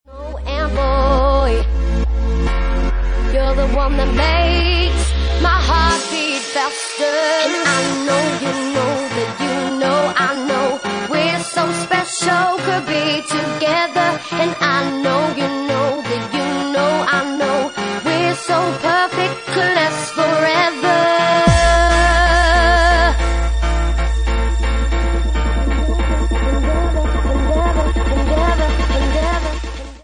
Bassline House